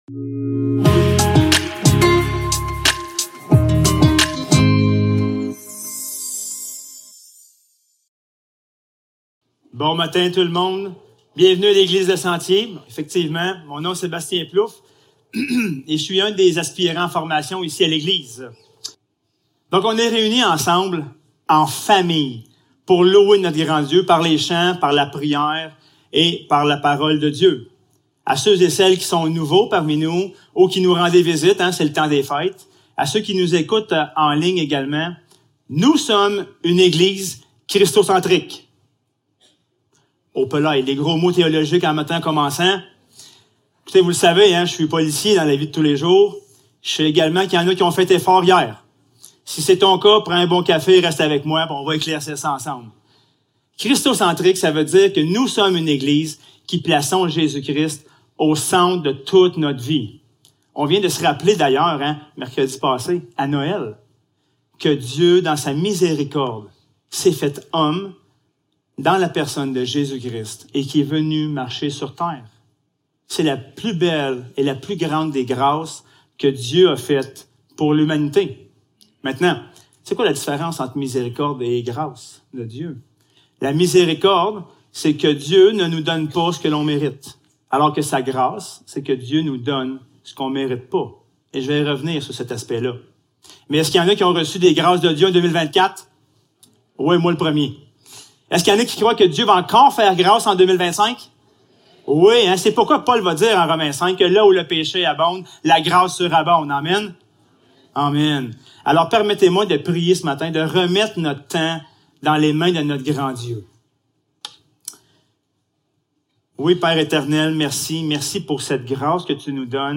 Passage: Luc 9.51 Service Type: Célébration dimanche matin